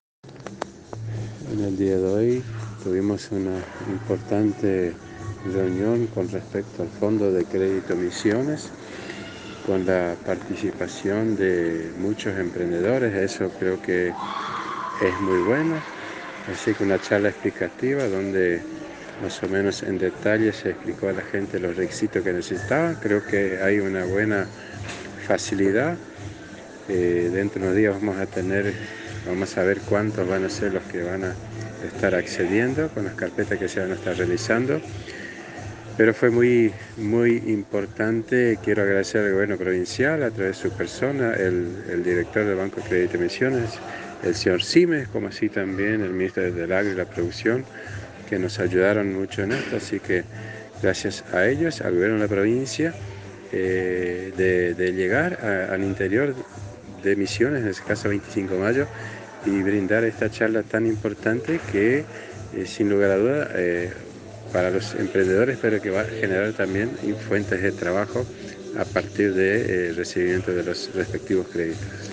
Audio: Intendente Mario Lindemann